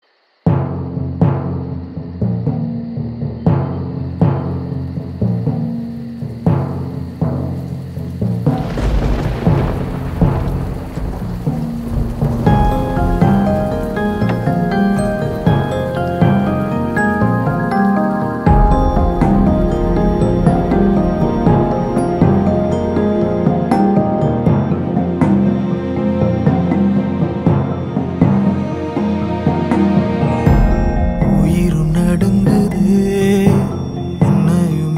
mass ringtone